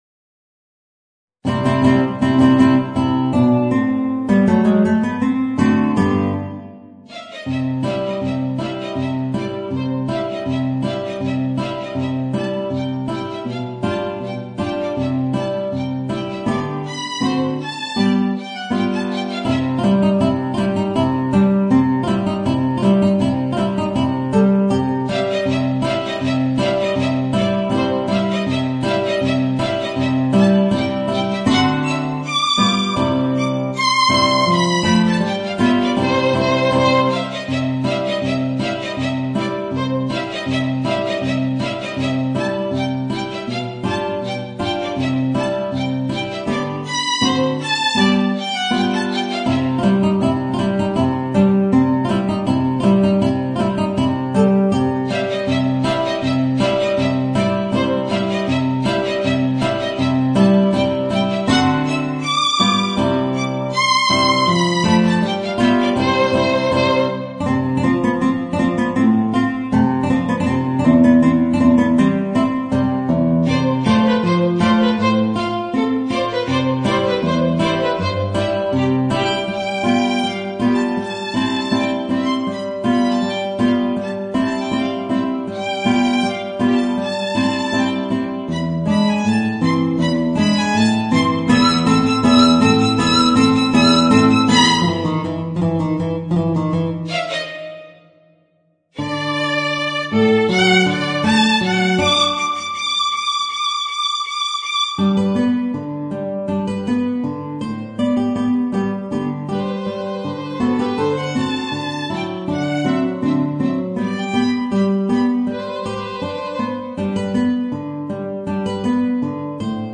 Voicing: Guitar and Violin